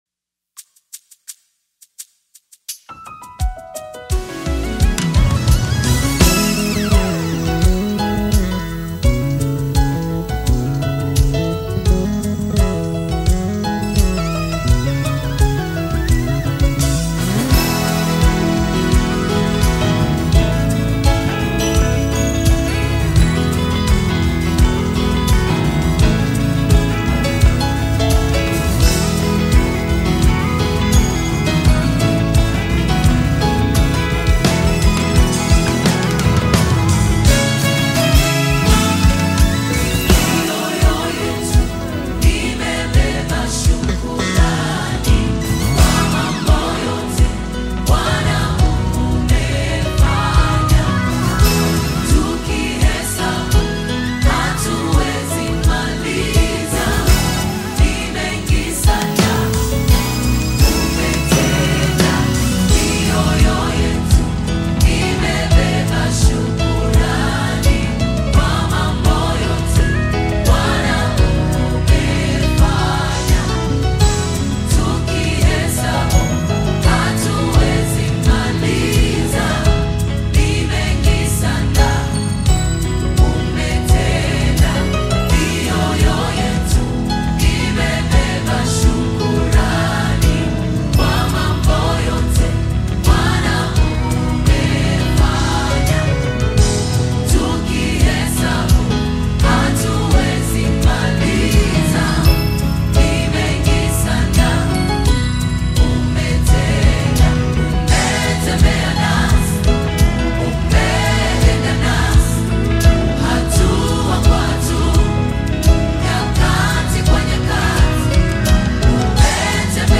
Nyimbo za Dini music
Gospel music track
Tanzanian Gospel group